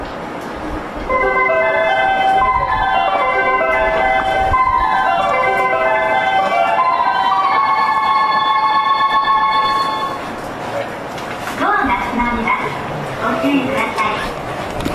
メロディーは一般的です。